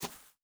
Shoe Step Grass Medium B.wav